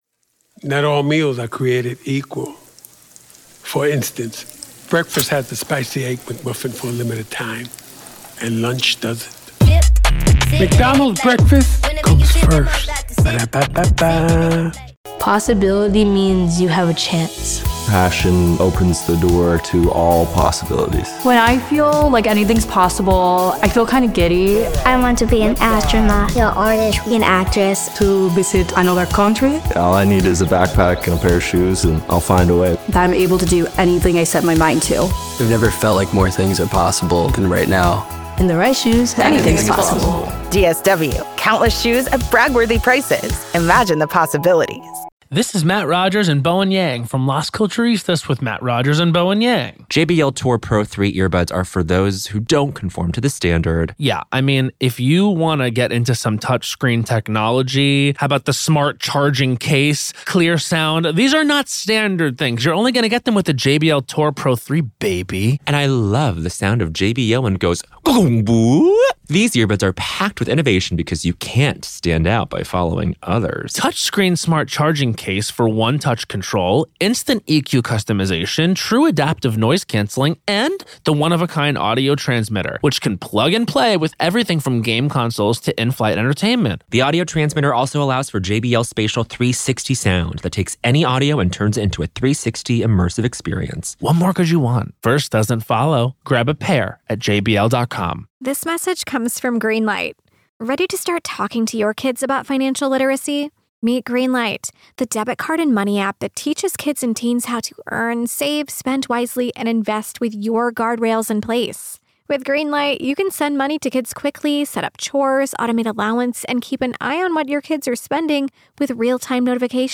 True Crime Today | Daily True Crime News & Interviews / Legal Analysis: Could Inaction Be the Crime in the Karen Read Case?